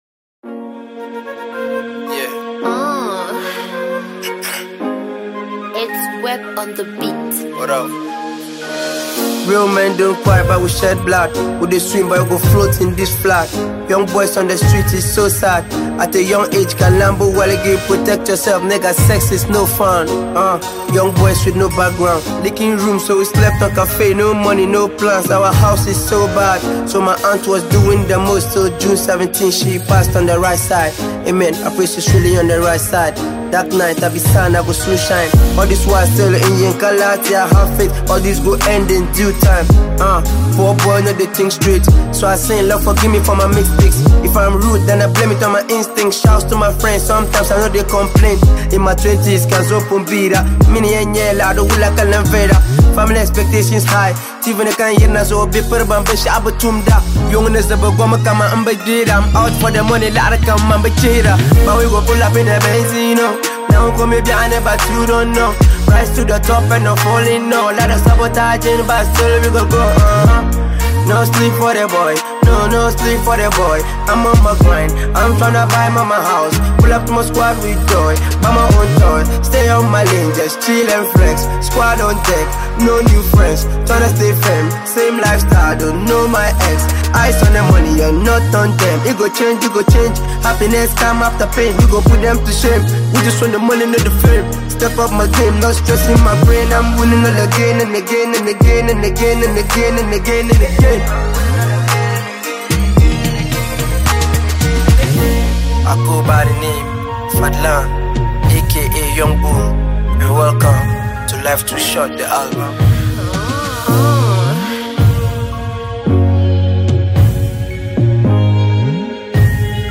a fast-rising Ghanaian young rapper